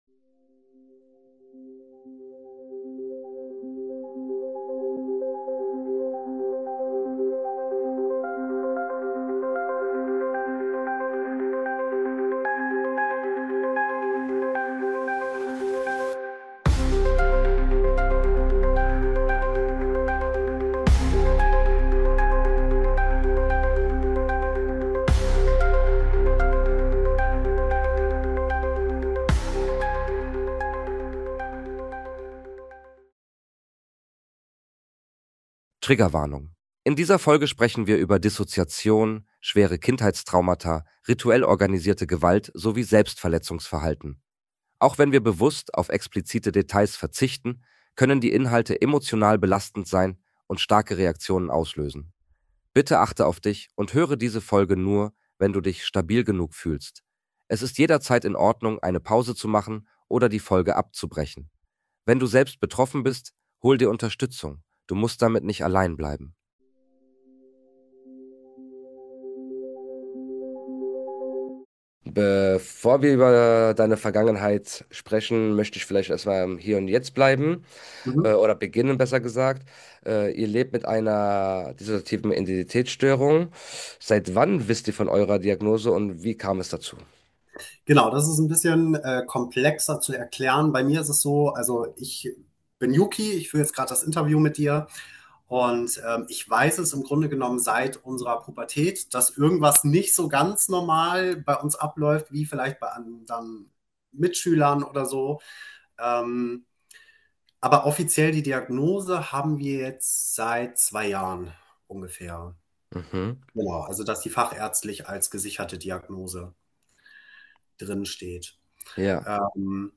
Statt nach Antworten zu suchen, liegt ihr Fokus auf Aufklärung: über Dissoziation, über die Realität von Trauma und über eine Perspektive, die selten gehört wird – die eines männlichen Betroffenen. Wir sprechen über den Alltag mit DIS, über innere Kommunikation, über Selbstfürsorge und den Umgang mit belastenden Impulsen.